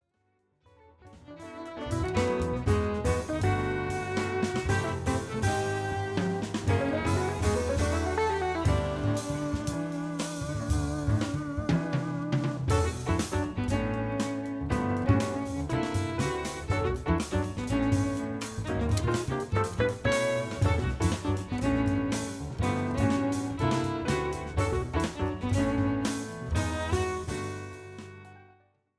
Recorded principally at Van Gelder Studios
Piano, Keyboards Composer
Bass
Drums
Violin